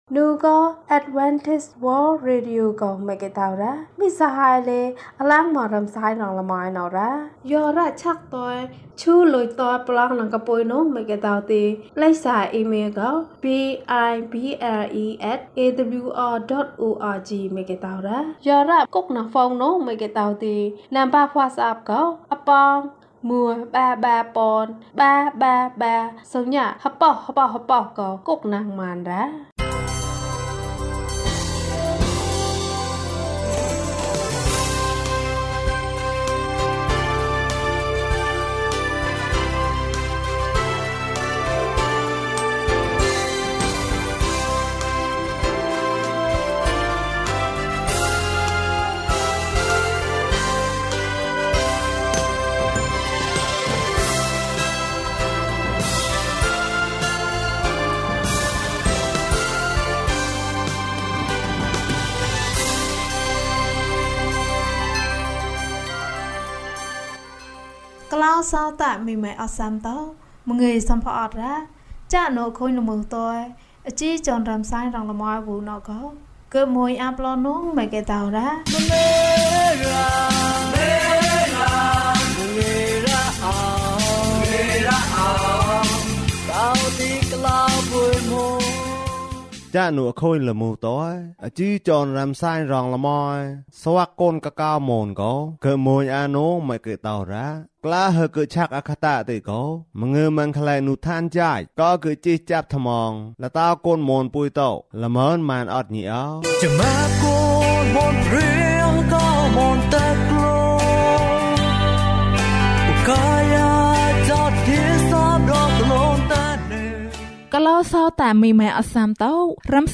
မနက်ဖြန်အတွက် စိတ်မပူပါနဲ့။ ကျန်းမာခြင်းအကြောင်းအရာ။ ဓမ္မသီချင်း။ တရားဒေသနာ။